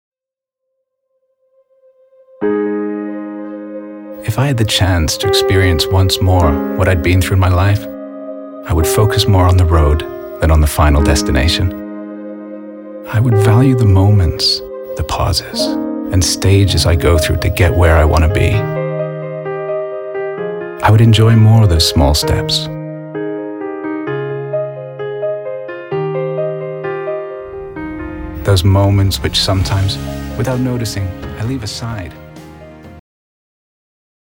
reflective, gravelly, assured